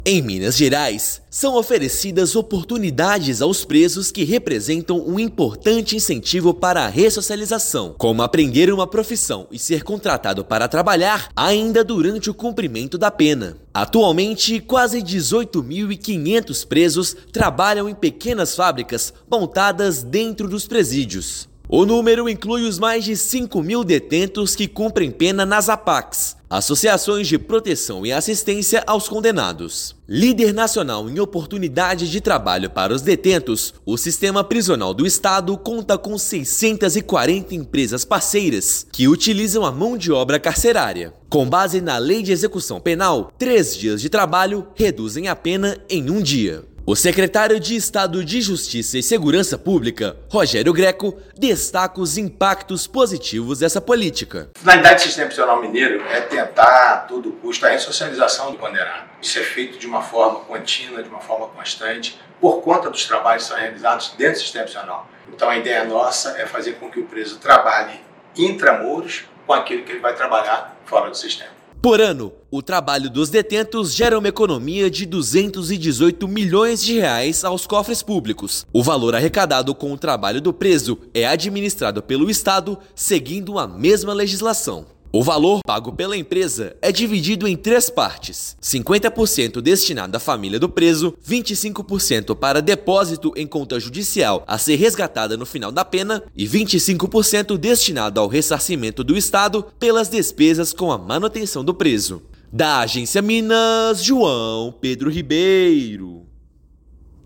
Atualmente, mais de 18 mil detentos trabalham durante o cumprimento da pena, gerando economia de mais de R$ 218 milhões aos cofres públicos. Ouça matéria de rádio.